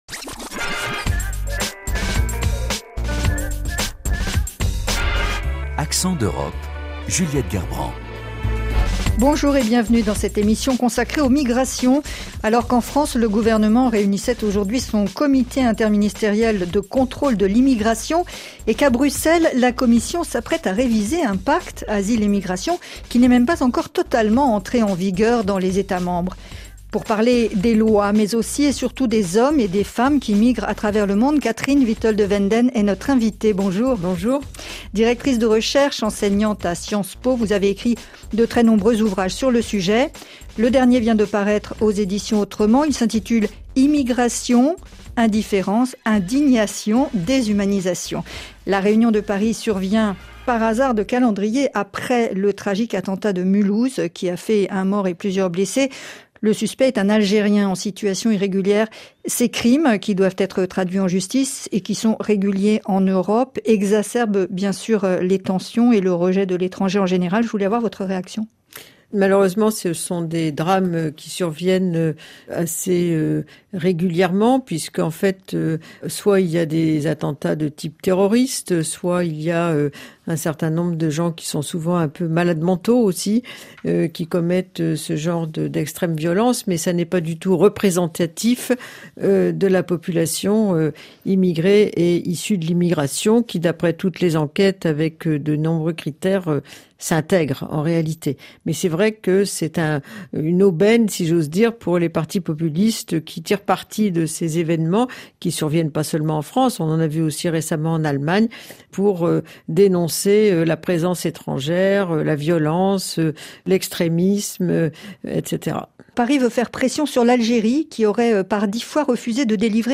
Et le reportage en Grèce